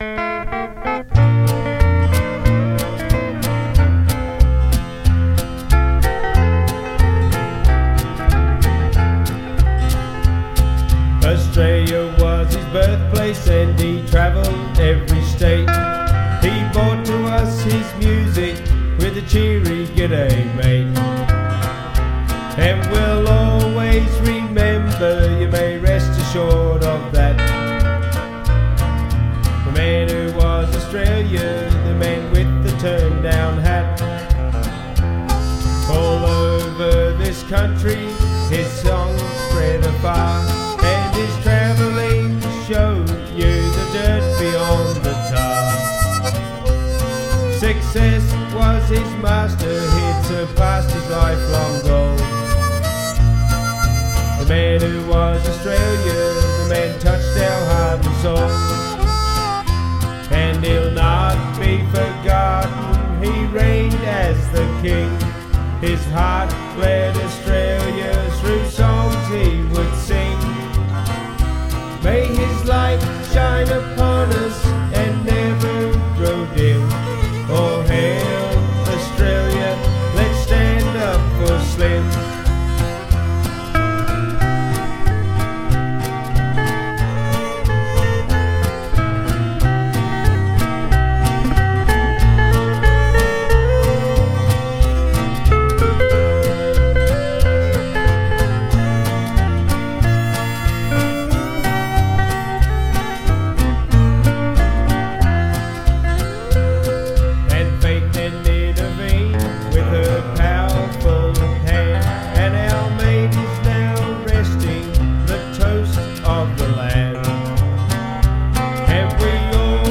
bush balladeers
heartfelt tribute song